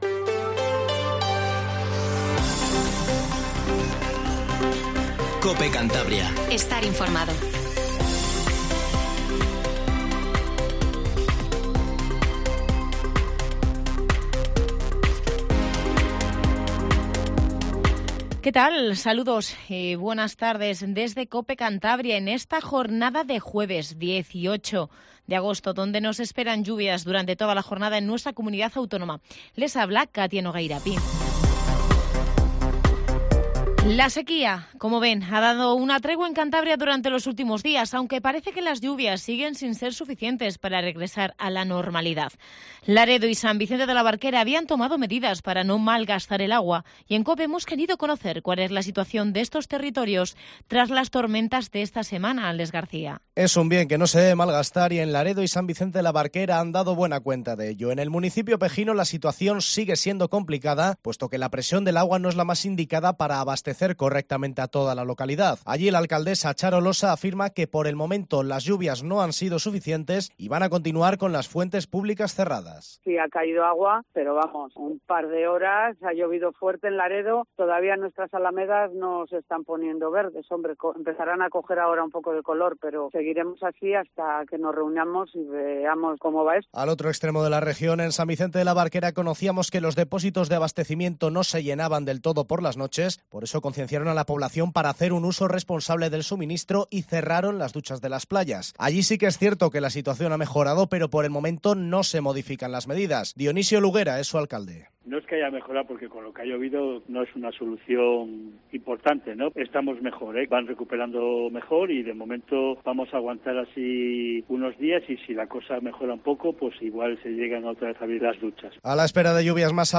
Informativo Regional 1420